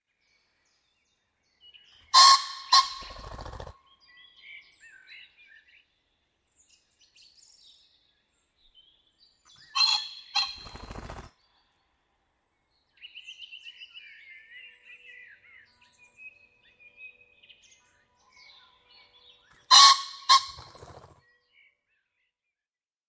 Faisan de colchide - Mes zoazos
faisan-de-colchide.mp3